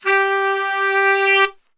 G 2 SAXSWL.wav